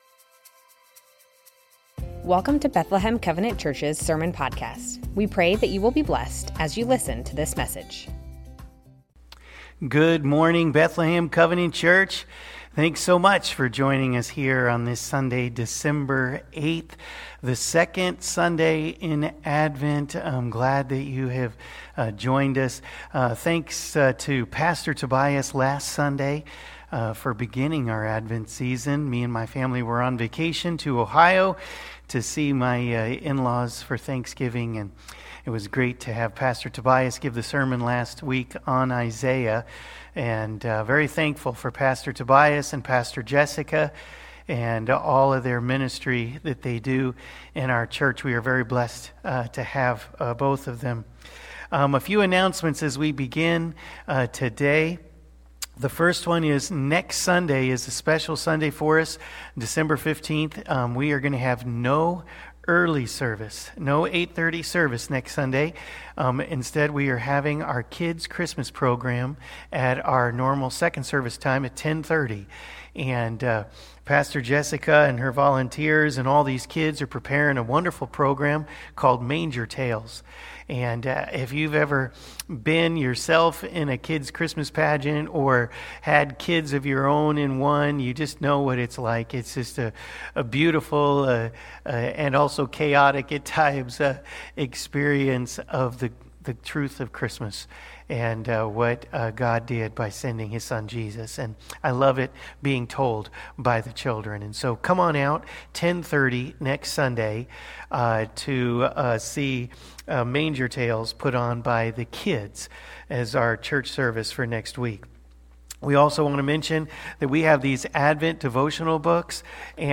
Bethlehem Covenant Church Sermons Advent - From the line of David Dec 08 2024 | 00:37:39 1x 00:00 / 00:37:39 Subscribe Share Spotify RSS Feed Share Link Embed